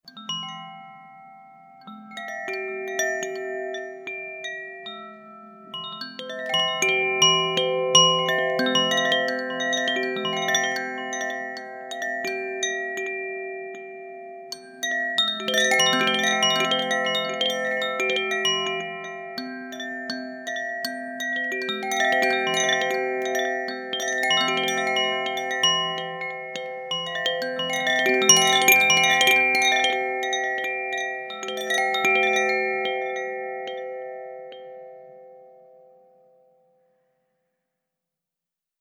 • koshi wind chimes terra sounds.wav
koshi_wind_chimes_terra_sounds_r0G.wav